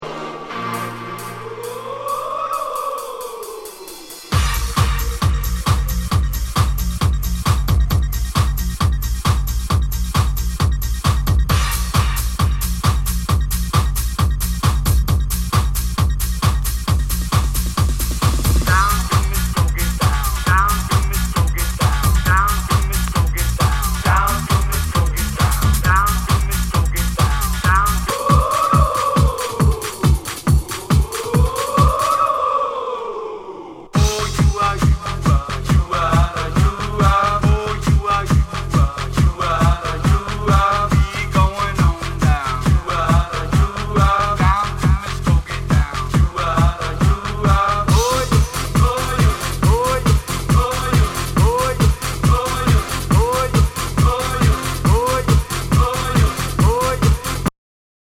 HOUSE/TECHNO/ELECTRO
ナイス！ユーロ・ハウス！
全体にチリノイズが入ります